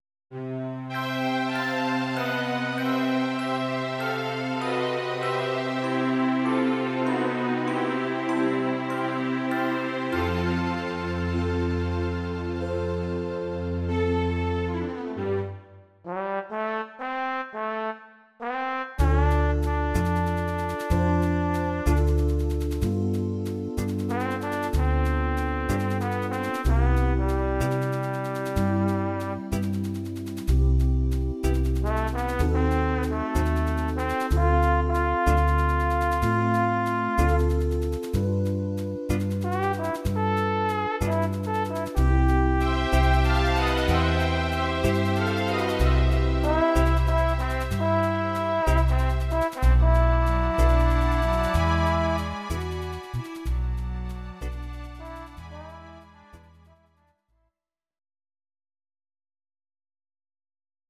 Audio Recordings based on Midi-files
Our Suggestions, Pop, Oldies, Jazz/Big Band, 1950s